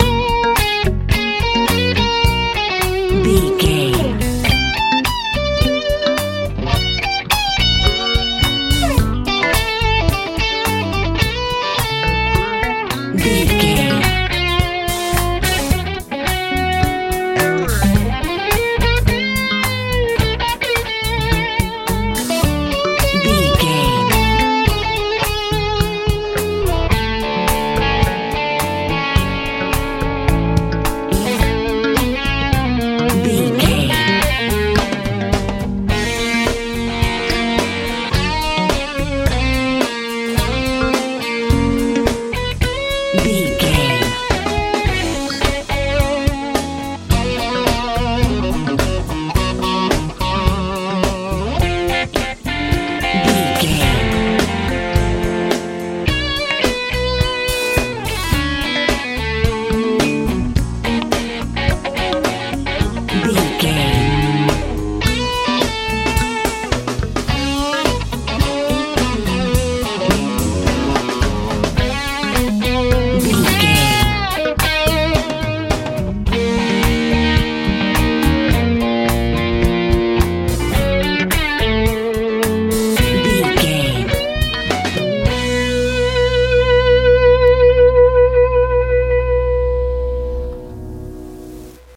rocking funk feel
Ionian/Major
F♯
bright
cool
piano
electric guitar
bass guitar
drums
80s
90s
sweet
happy